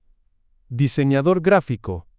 I experimented with basic text-to-speech options, but I found a way to create the audio of my desired text with natural-sounding pronunciations.
GraphicDesigner-Spanish.wav